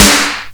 Snare Sample G Key 59.wav
Royality free snare one shot tuned to the G note. Loudest frequency: 4029Hz
snare-sample-g-key-59-exJ.ogg